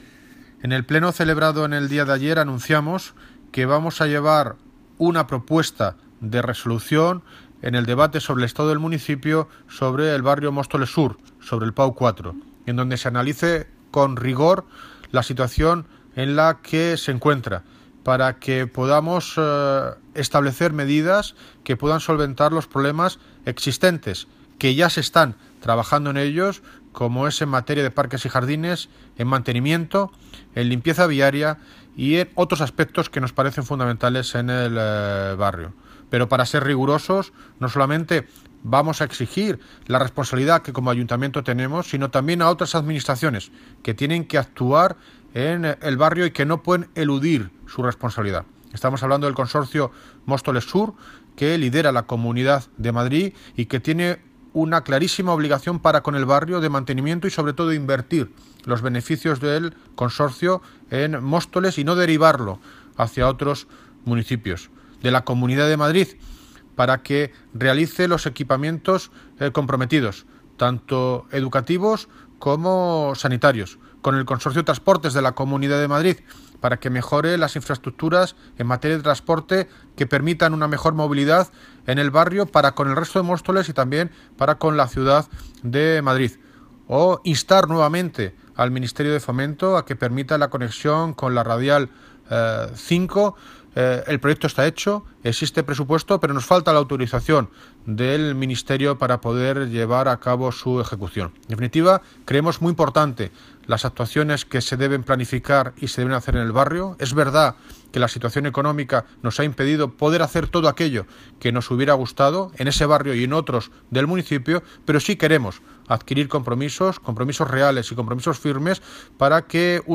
Audio - David Lucas (Alcalde de Móstoles) Sobre propuesta mejora del PAU-4